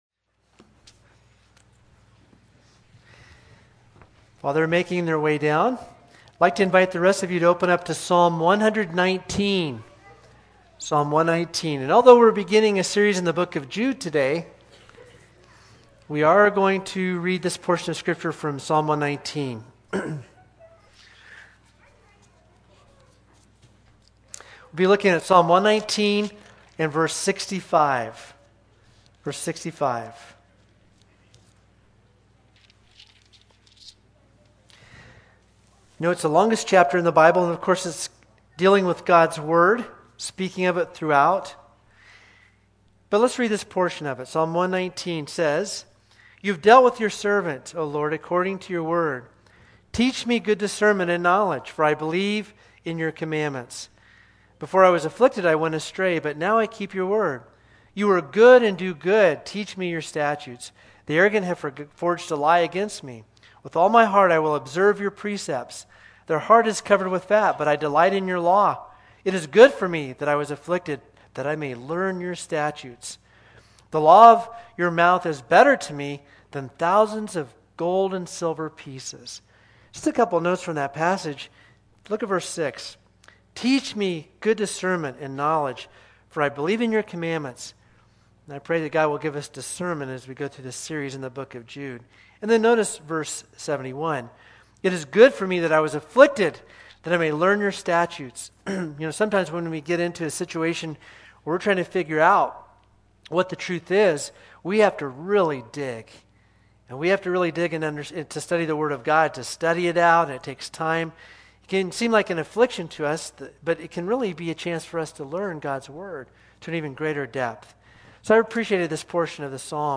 8_7-22-sermon.mp3